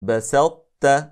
8. Ţā' [الطاء — ط] in i Tā' [التاء — ت]:
Det är ofullständig idgham (إدغامٌ ناقص); eftersom en stark bokstav inte går in helt i en svag bokstav, så brukade araberna slå samman det icke-vokaliska (Ţā' — ط) i (Tā' — ت), samtidigt som det bevarade egenskapen av vidhäftning (iţbāq — الإطباق) från det, vilket händer när reciteraren stänger hans/hennes tunga på en (Ţā' — ط) utan qaqalah och sedan tar han/hon bort den från en vokal (Tā' — ت), som i: